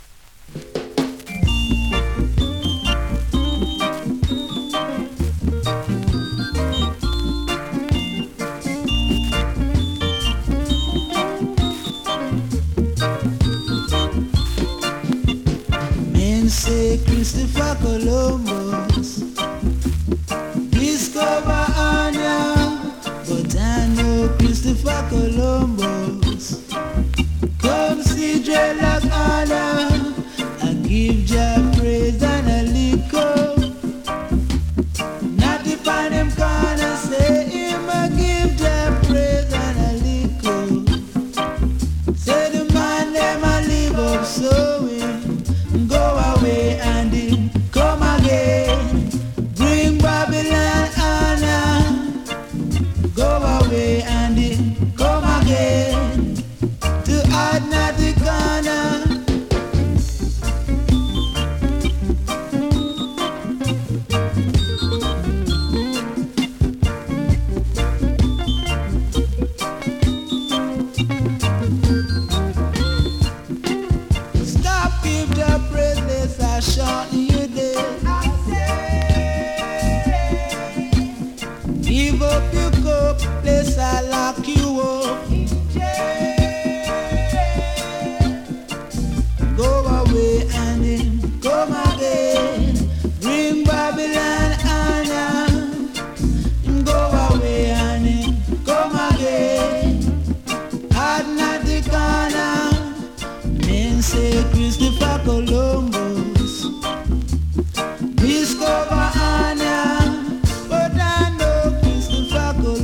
スリキズ、ノイズ比較的少なめで